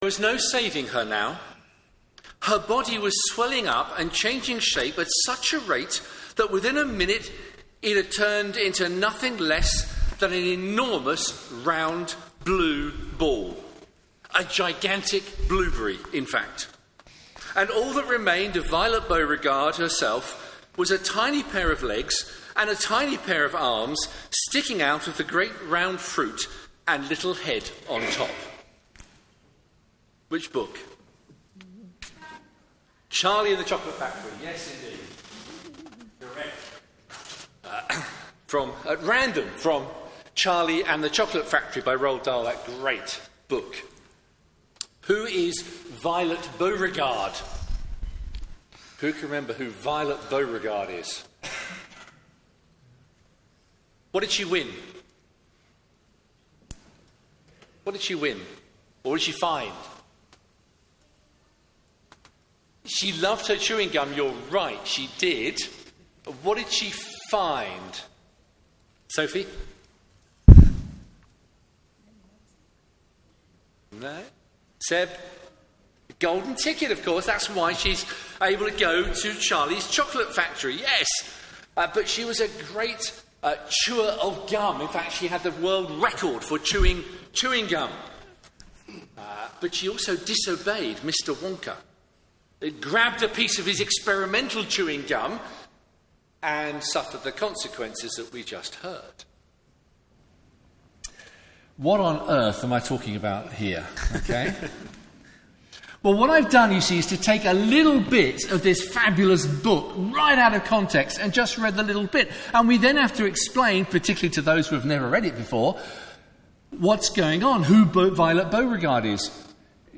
Media for Morning Service on Sun 05th Jan 2025 10:30 Speaker: Passage: Luke 7:36-50 Series: Luke Theme: Sermon In the search box please enter the sermon you are looking for.